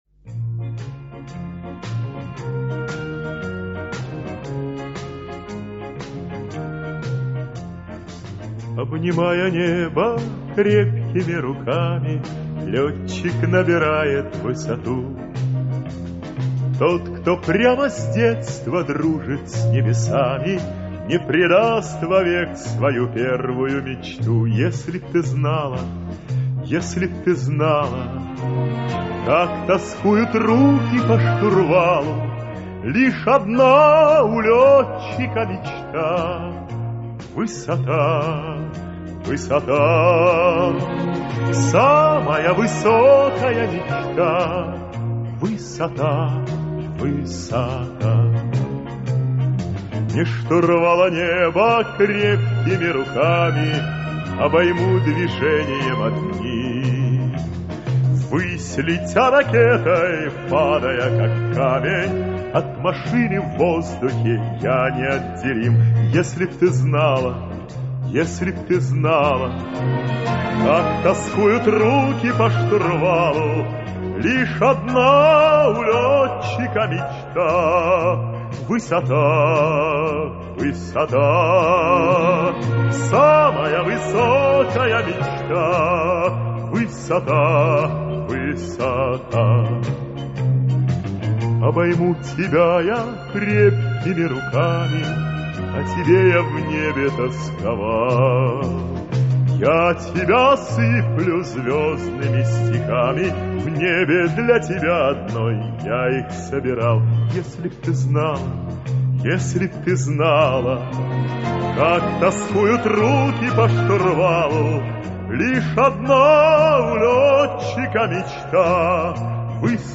Режим: Mono